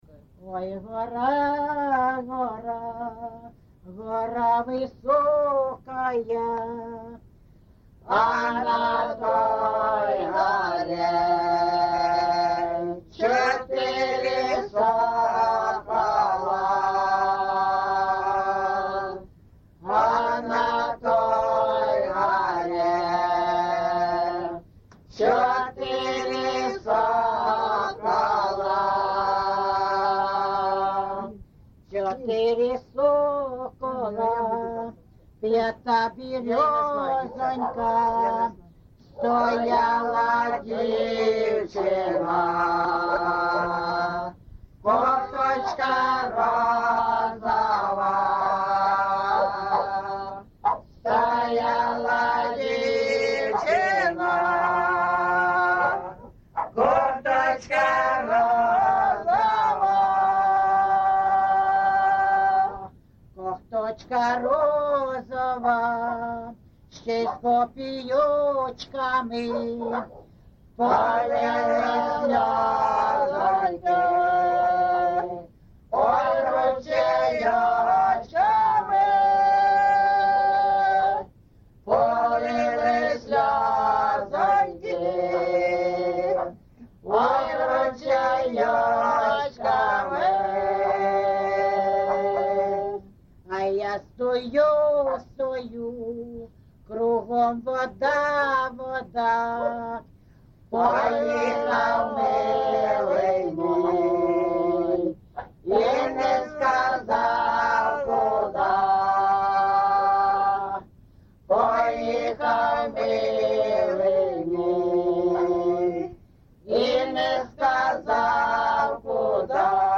ЖанрПісні з особистого та родинного життя
Місце записус. Андріївка, Великоновосілківський район, Донецька обл., Україна, Слобожанщина